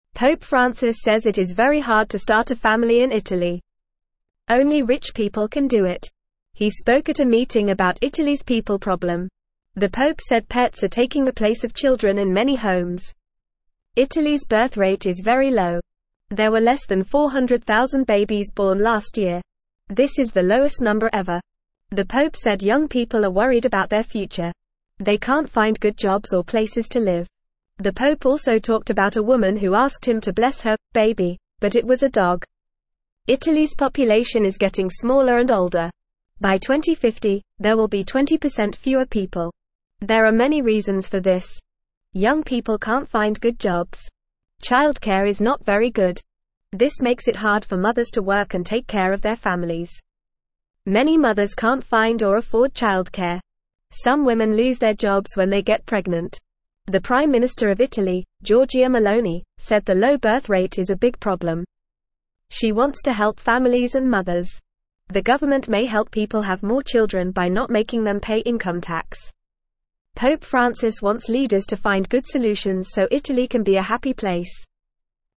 Fast
English-Newsroom-Beginner-FAST-Reading-Pope-Talks-About-Italys-Baby-Problem.mp3